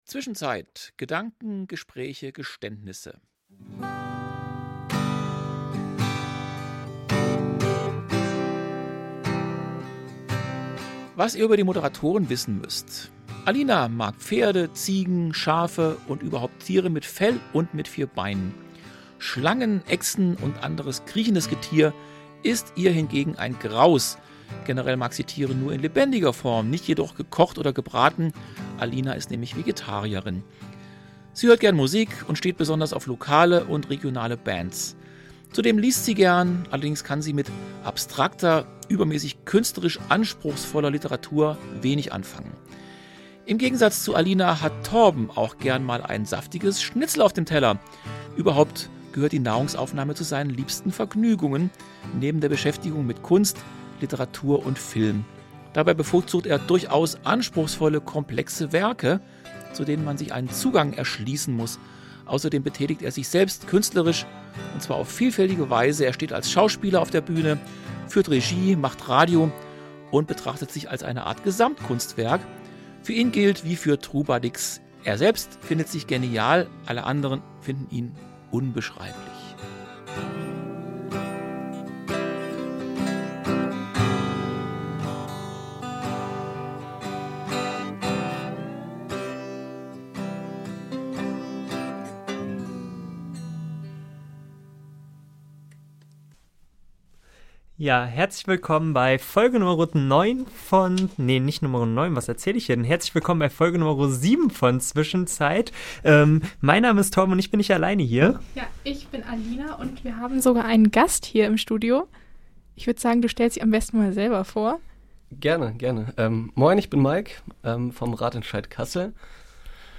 Darüber sprechen wir mit den Initiatoren des Kasseler Radentscheids.